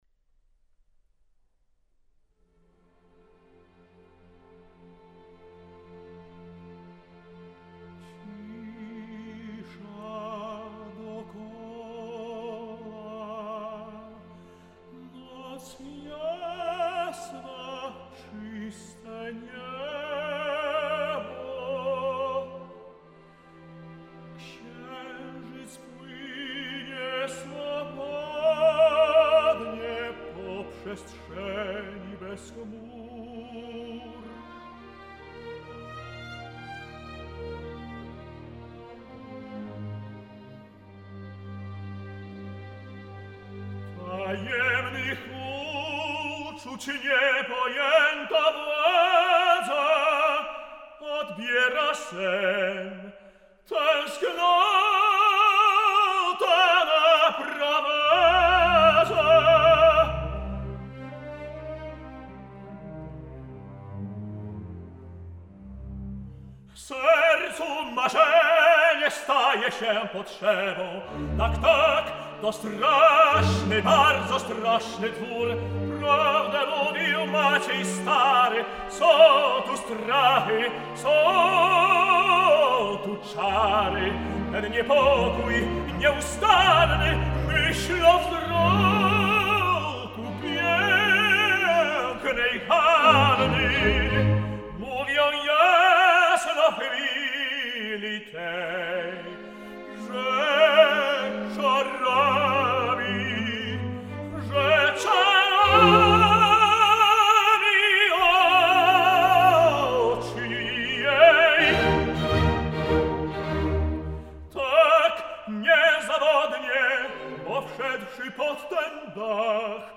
Пётр Бечала. Ария Стефана из оперы С.Монюшко "Зачарованный замок" ("Страшный двор")